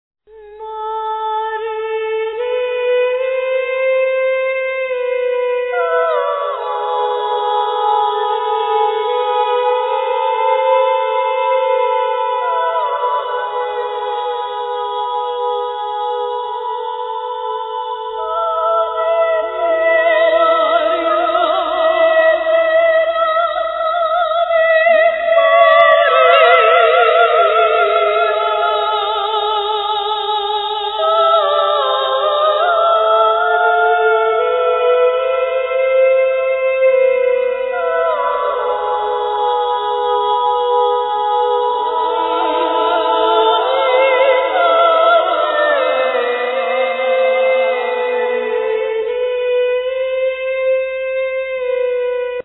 Yang T'Chin,Santoor,Rhythm,Voice
Voices, Snare drums, Percussions
Keyboards, Timpani, Shamanic Drums, Percussions
Voices, Timpani, Singing bows, Percussions